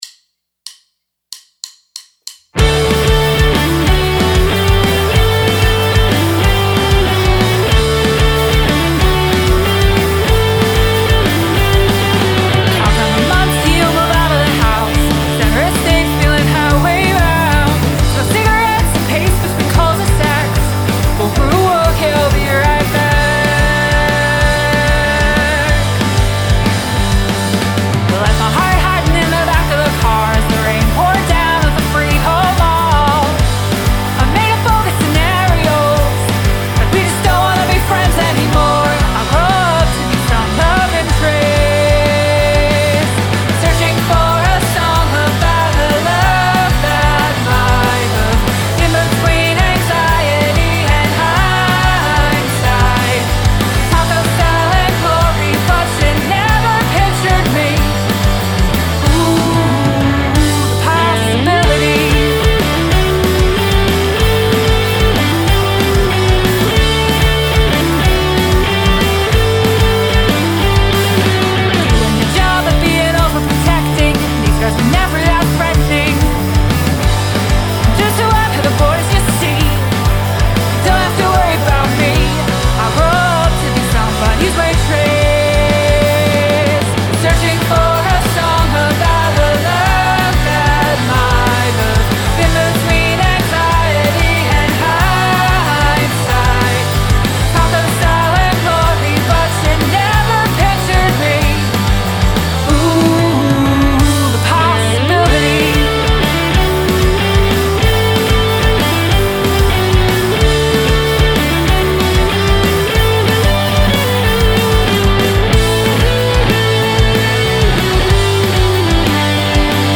punk rockers
alluringly melodic